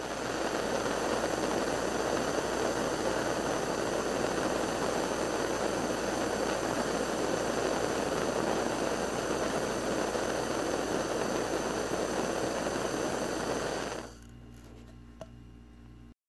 torch.wav